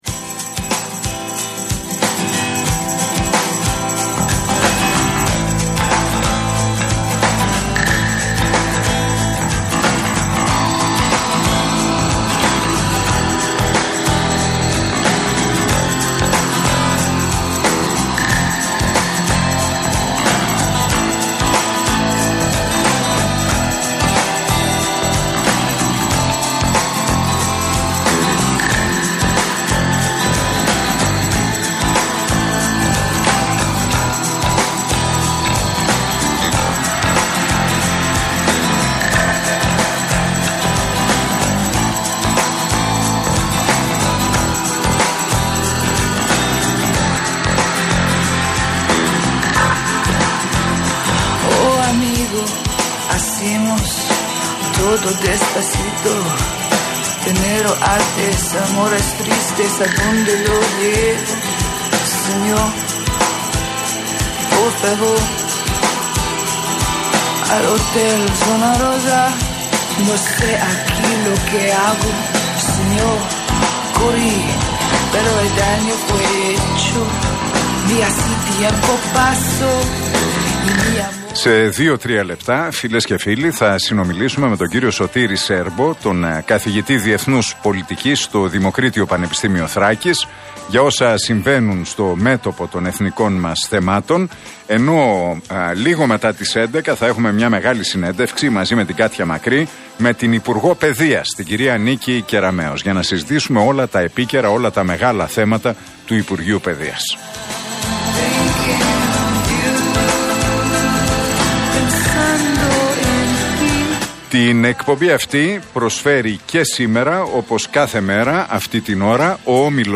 Ακούστε την εκπομπή του Νίκου Χατζηνικολάου στον RealFm 97,8, τη Δευτέρα 4 Ιουλίου 2022.